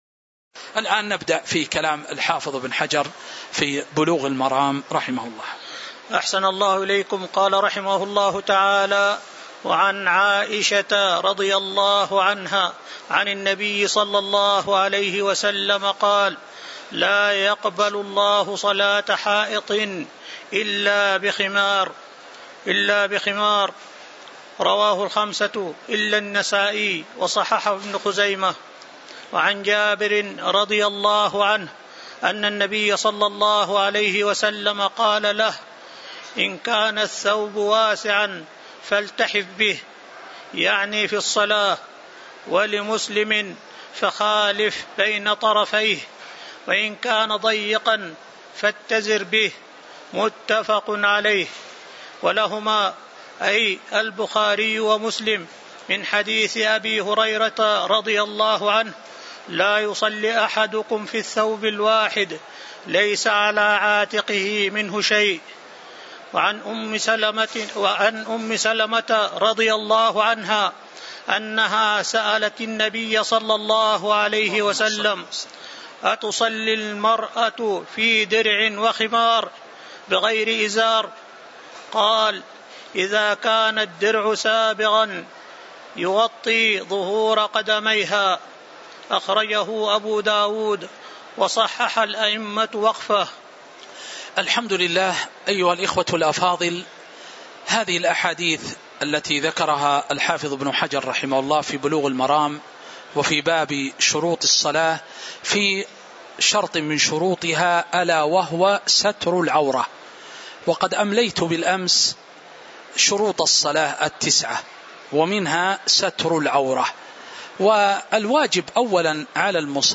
تاريخ النشر ١١ صفر ١٤٤٥ هـ المكان: المسجد النبوي الشيخ